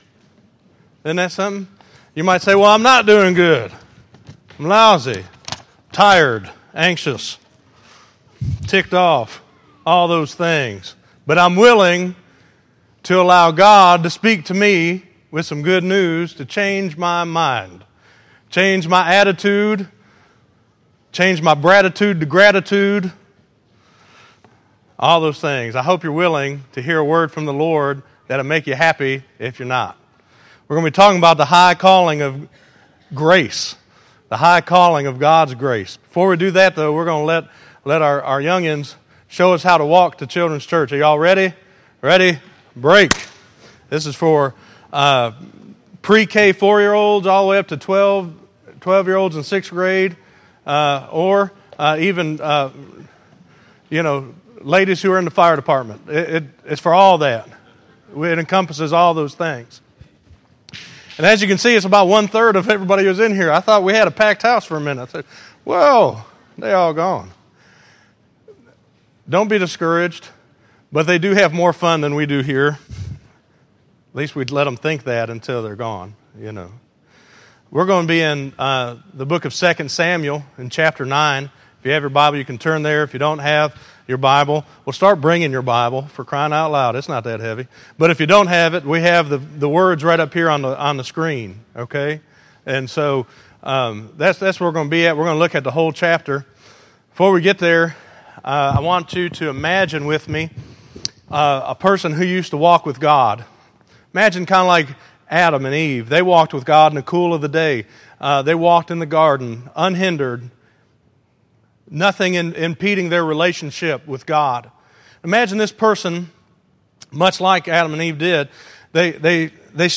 The High Calling of Grace; Is God Calling You? - Messages from Christ Community Church.
Listen to The High Calling of Grace Is God Calling You - 03_02_14_Sermon.mp3